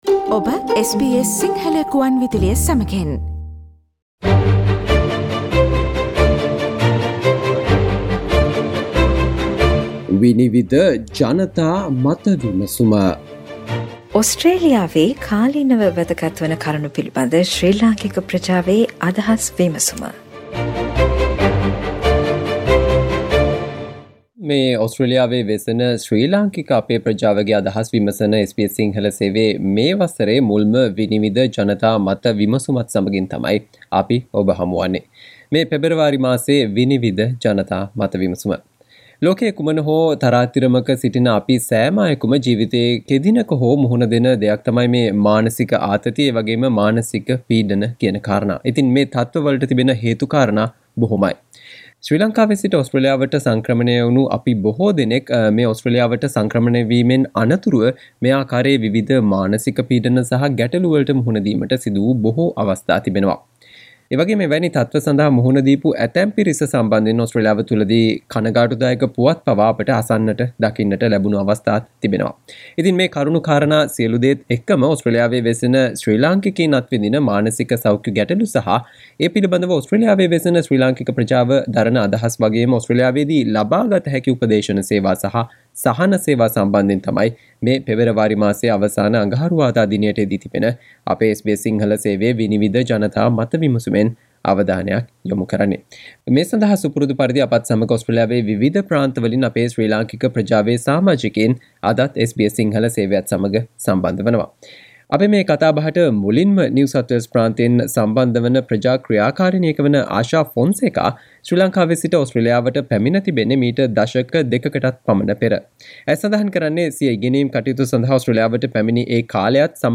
ඕස්ට්‍රේලියාවේදී අත්විඳින මානසික ගැටලු සහ අත්දැකීම් ගැන ඕස්ට්‍රේලියාවේ සිටින ලාංකිකයින් කියූ දෑ :'විනිවිද' ජනතා මත විමසුම